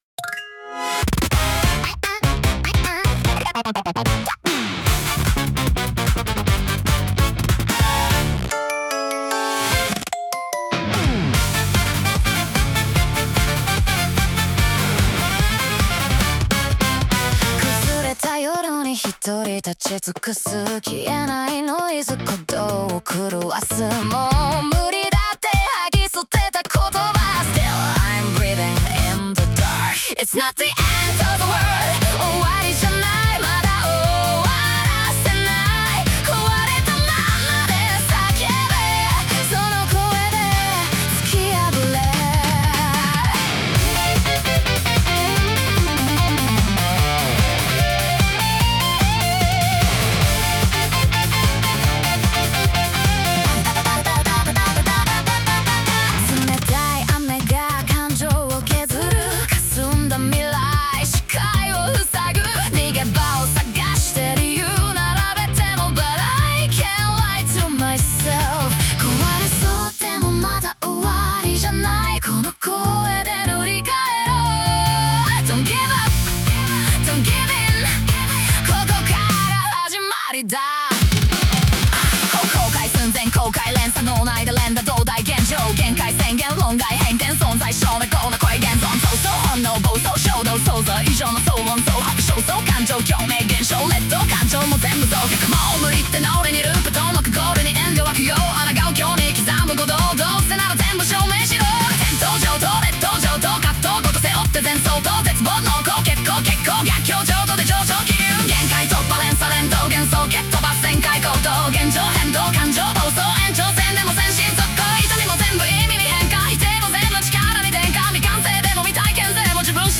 女性ボーカル
イメージ：アバンギャルド・ポップ,エレクトロニック・ポップ,グリッチ・ポップ,女性ボーカル